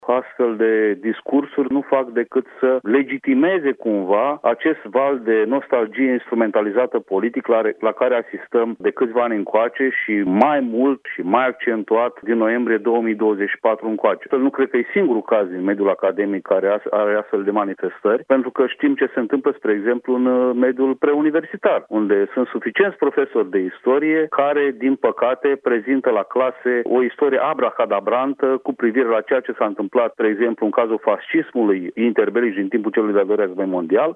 Directorul IICCMER, Daniel Șandru: Mai sunt destui profesori în mediul preuniversitar care prezintă la clase o istorie abracadabrantă